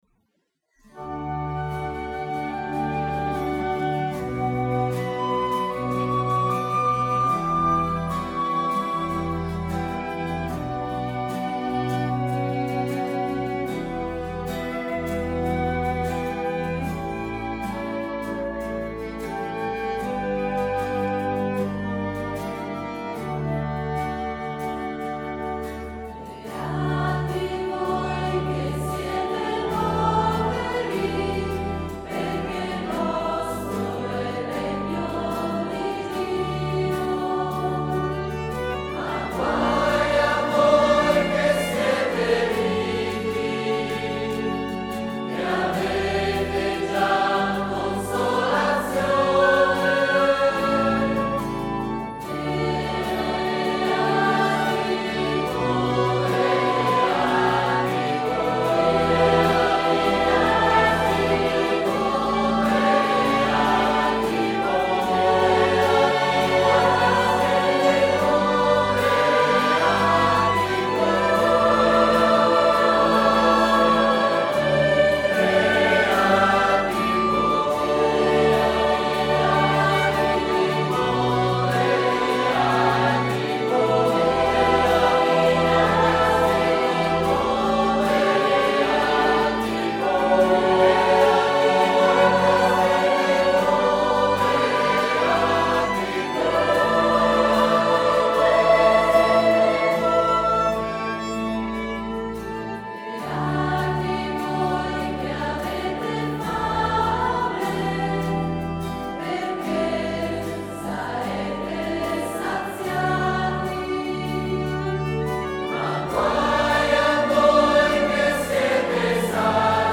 Organo
Violino
Flauto traverso
Flauto dolce
Chitarra
Soprani
Contralti
Tenori
Bassi